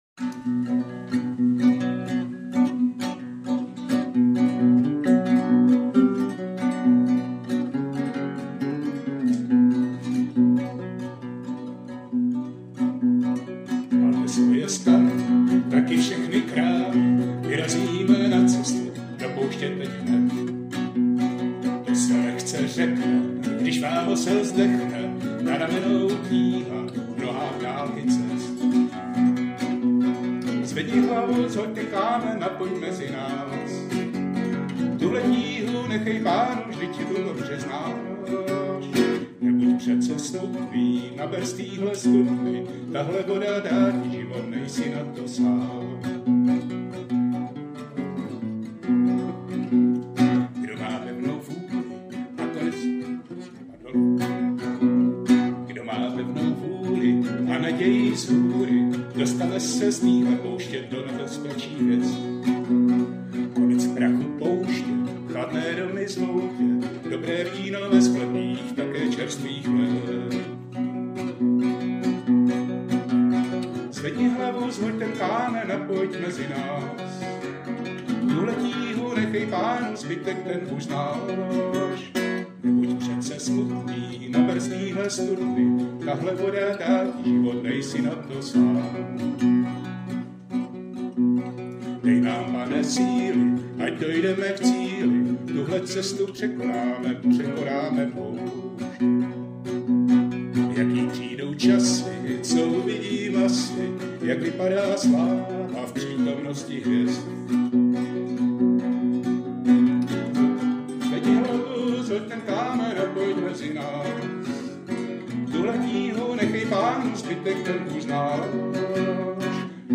Vyzpívaná modlitba v době předvelikonoční jistě potěší.